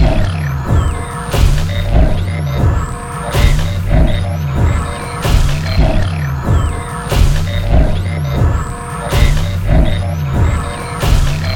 lark.ogg